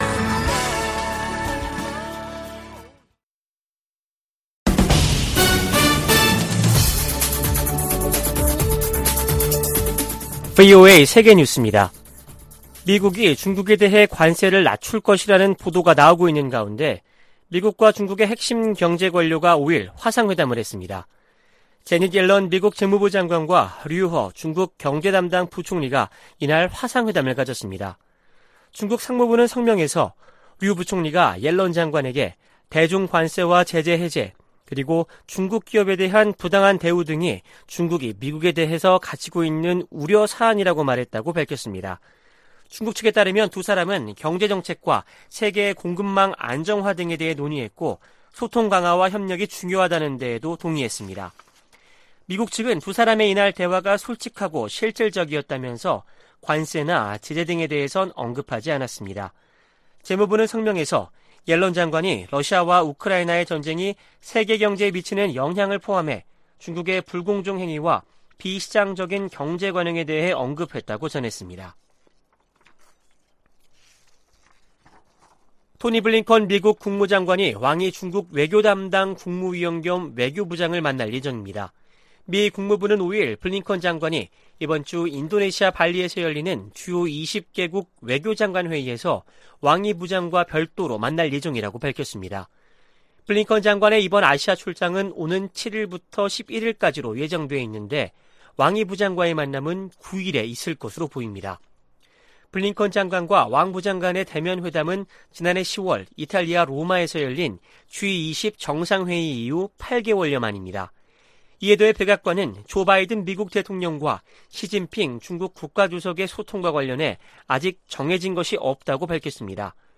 VOA 한국어 아침 뉴스 프로그램 '워싱턴 뉴스 광장' 2022년 7월 6일 방송입니다. 한국 국방부는 5일 미 공군 스텔스 전투기 F-35A 6대가 한반도에 전개됐다고 밝혔습니다. 북한의 7차 핵실험 가능성이 제기되는 가운데 미 공군 특수 정찰기들이 한반도와 일본, 동중국해 등에서 포착되고 있습니다. 미 하원에 타이완과 한국 등 인도태평양 동맹국들에 대한 방산물자 인도가 효율적으로 이뤄지도록 하는 법안이 발의됐습니다.